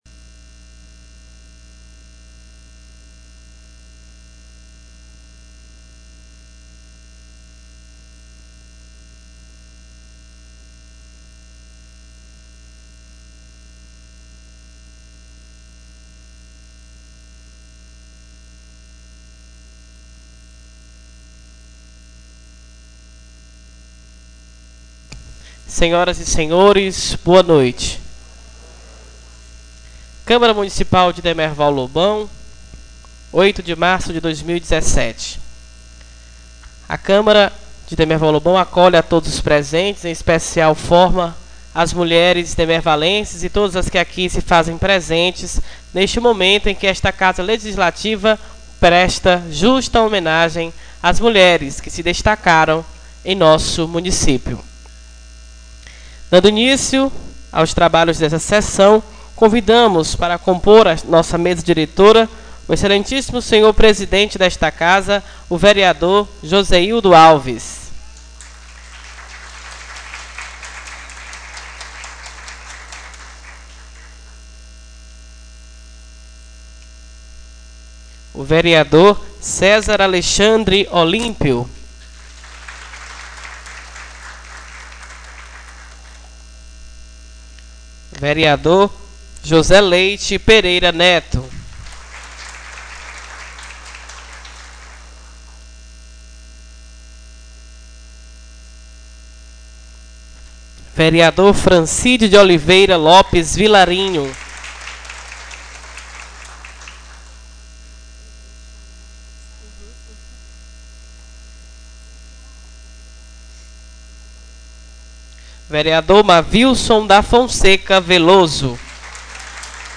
3ª SESSÃO SOLENE 08/03/2017